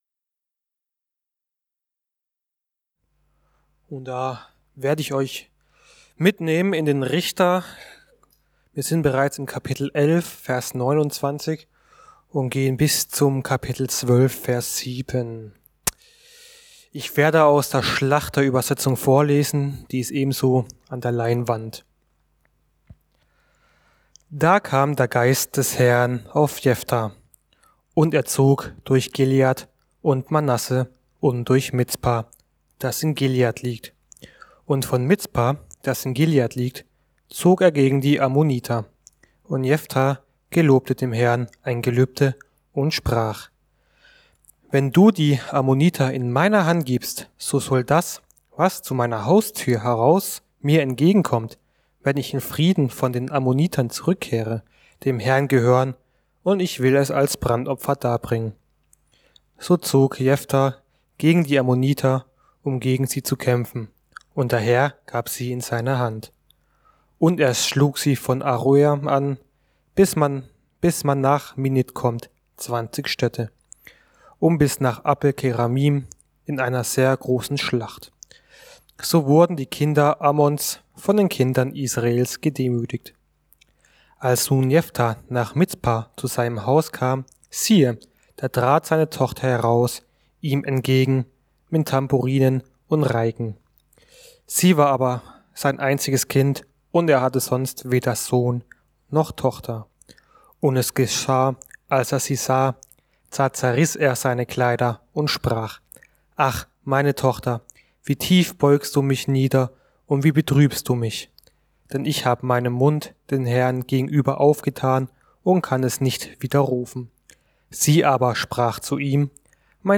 Fundament der Gnade ~ Mittwochsgottesdienst Podcast